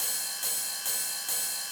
K-1 Ride.wav